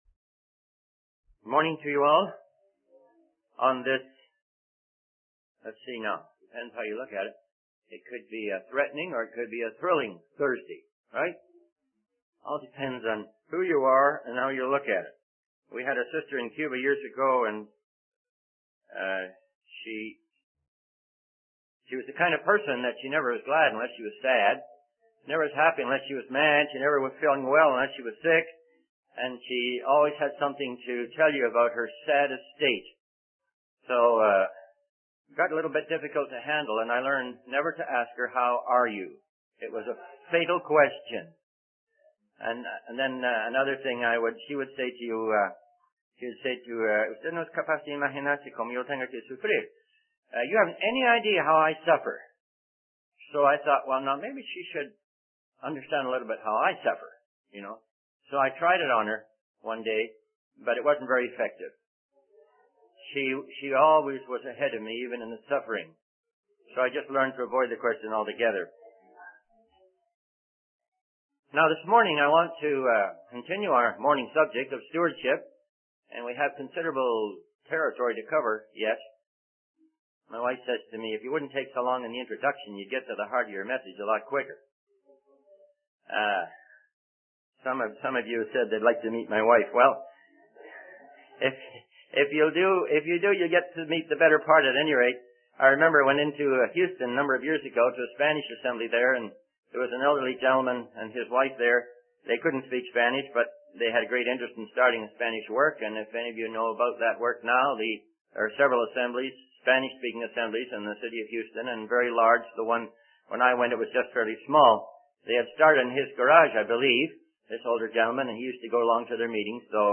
In this sermon, the preacher discusses how God reveals his wrath against those who have turned away from the truth and embraced lies. He emphasizes that God has manifested himself and his eternal power through creation.